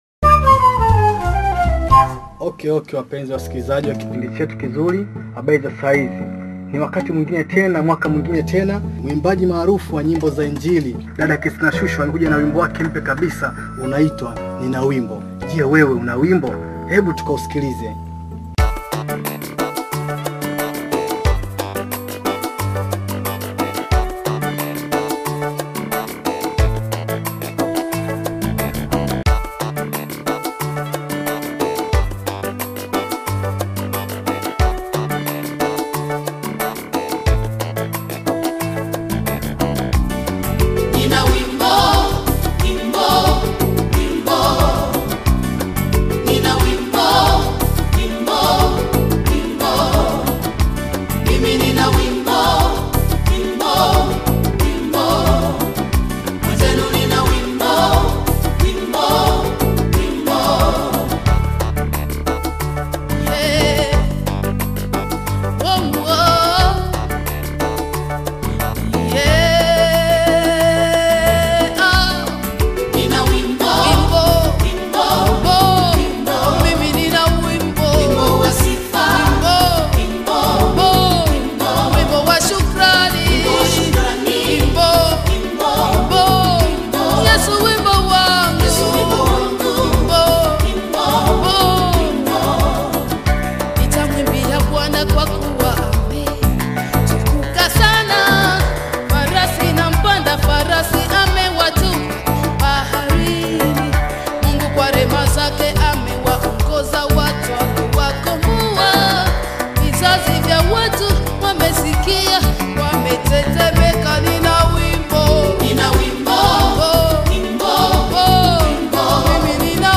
gospel
Both praise and worship songs.